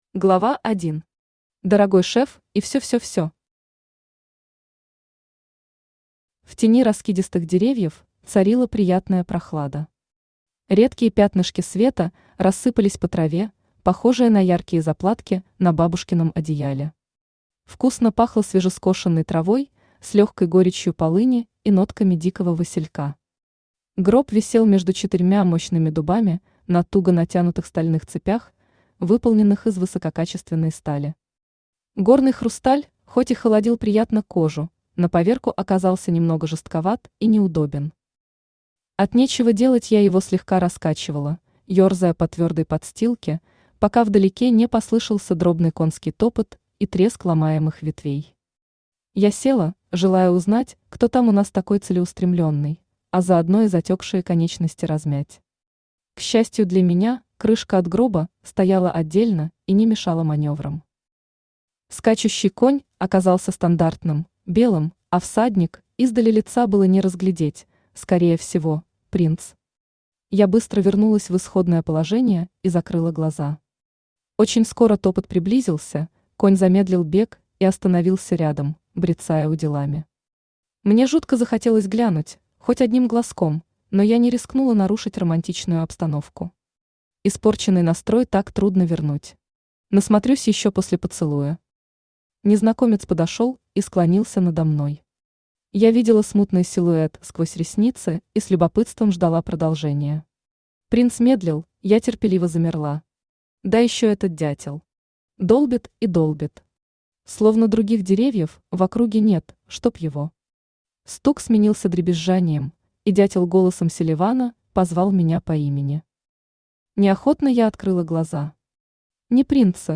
Аудиокнига Не будите спящих красавиц | Библиотека аудиокниг
Aудиокнига Не будите спящих красавиц Автор Таиска Кирова Читает аудиокнигу Авточтец ЛитРес.